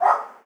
dog_bark_small_07.wav